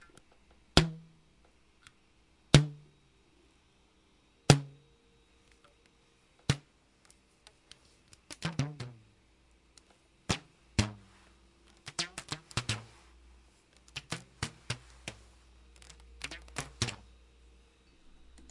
描述：使用Zoom H6录制橡皮筋
Tag: 贴紧 橡胶带